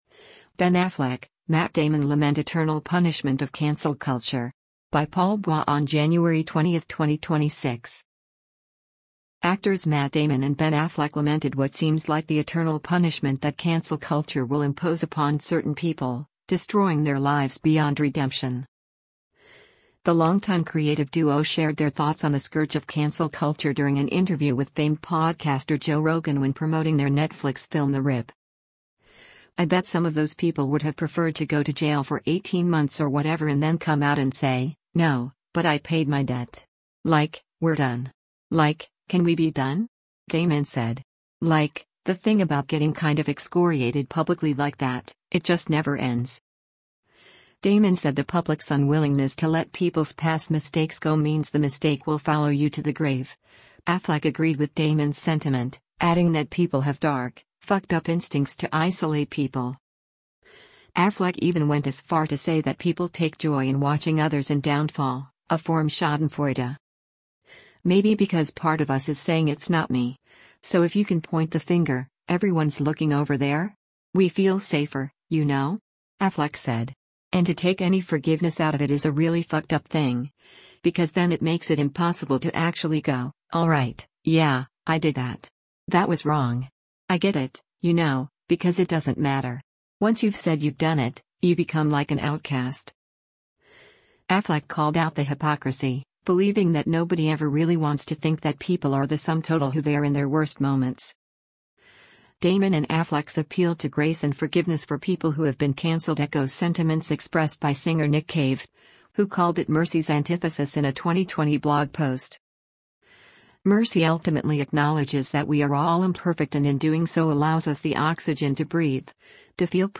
The longtime creative duo shared their thoughts on the scourge of cancel culture during an interview with famed podcaster Joe Rogan when promoting their Netflix film The Rip.